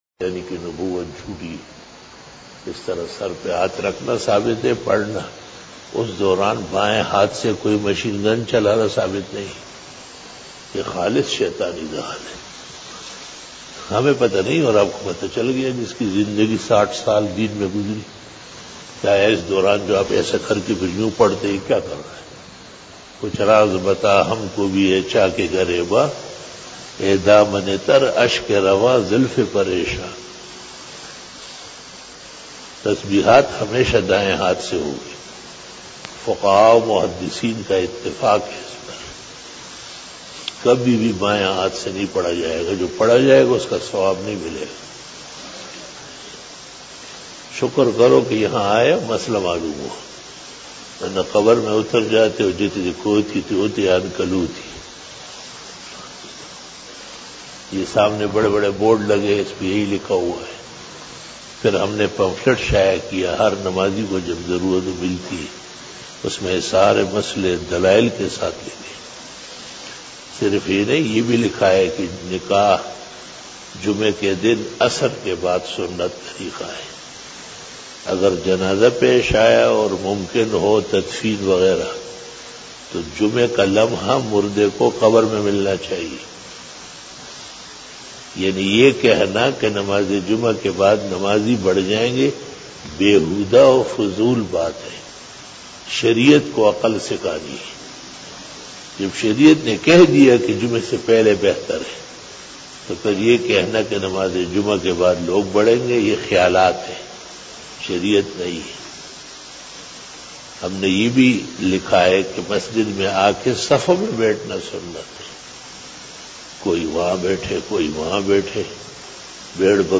After Namaz Bayan
بیان بعد نماز عصر بروز جمعہ